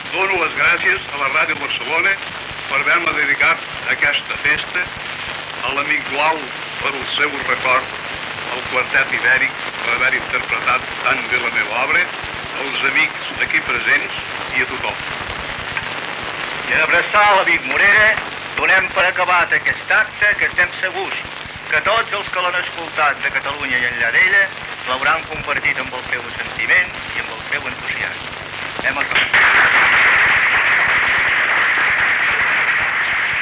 El músic Enric Morera agraeix l'homenatge que ha rebut i paraules de comiat d'Adrià Gual.